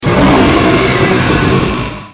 P3D-Legacy / P3D / Content / Sounds / Cries / 644.wav